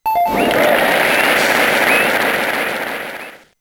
correctNoMusic.ogg